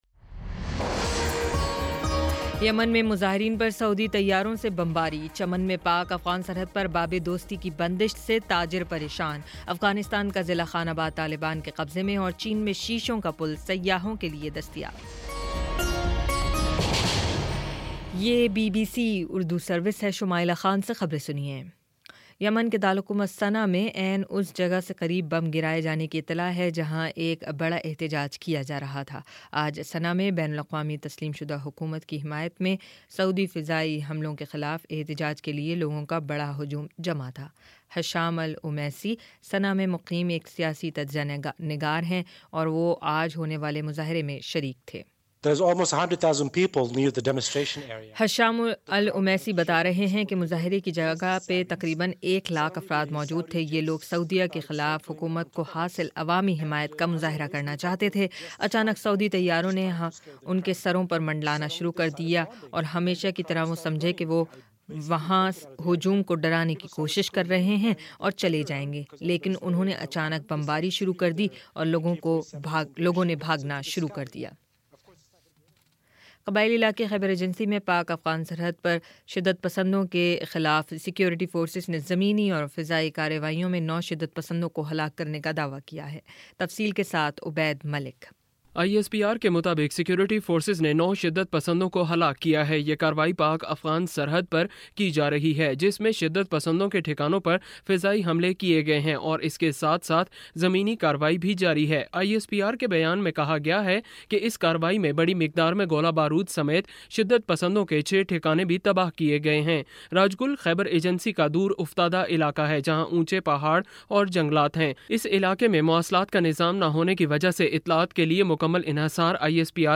اگست 20 : شام سات بجے کا نیوز بُلیٹن